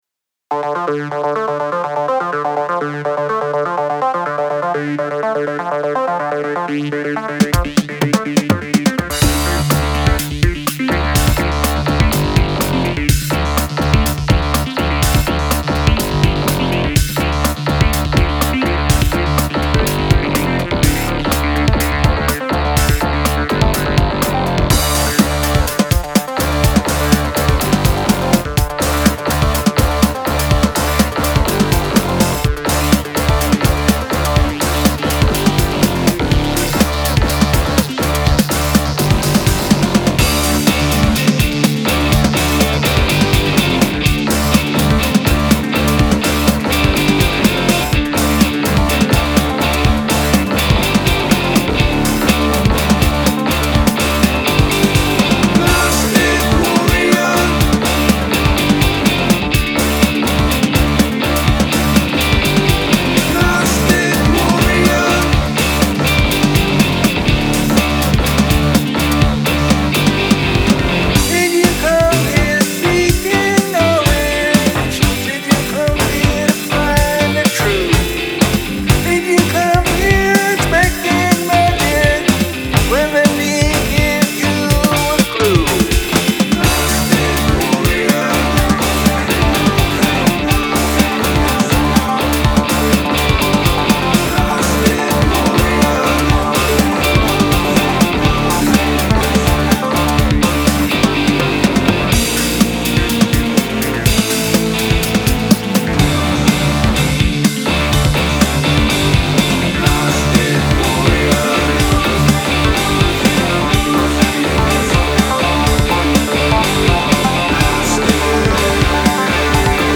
Industrial Rock Tune
I like the sounds and the overall balance. The hi hat on the left feels really close.
You're getting some high-end distortion (much like bad mp3 compression or autotune) Click to expand...
The vocals were recorded using a U47 (tube) clone through a Warm Audio 1073 very hot. So they are quite saturated.